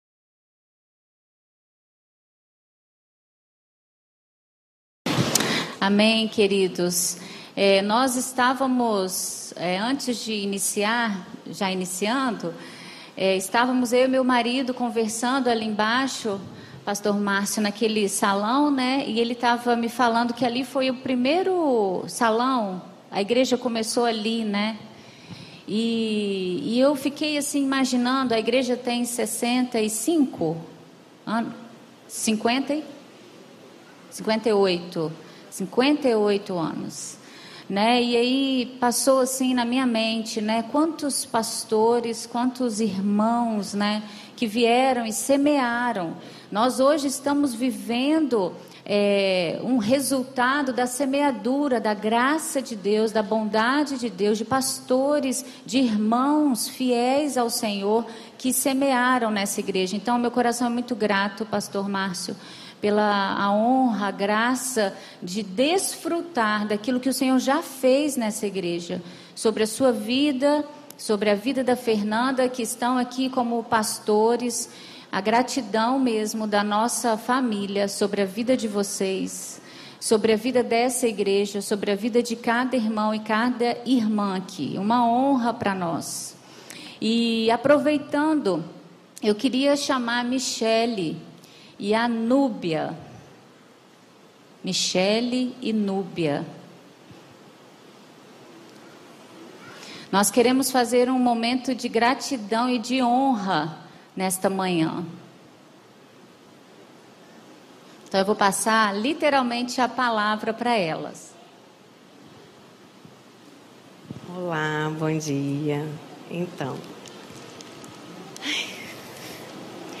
Mensagem